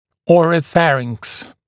[ˌɔːrə’færɪŋks][ˌо:рэ’фэринкс]ротоглотка